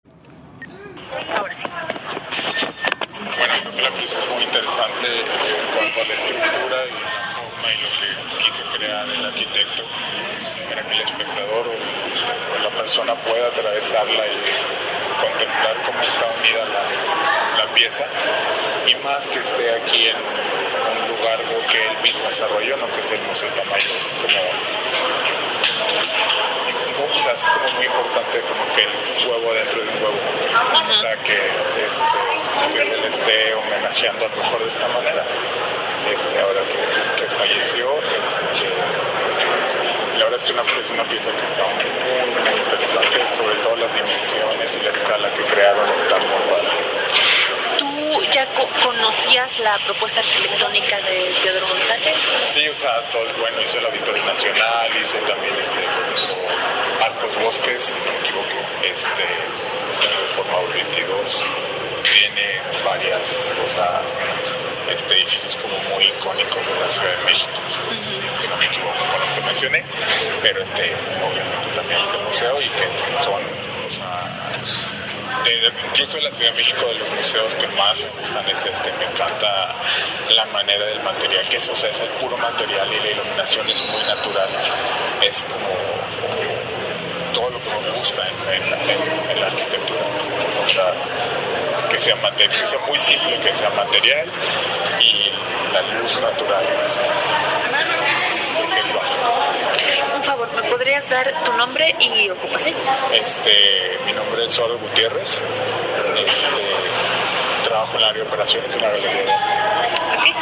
teodorotestimonio.mp3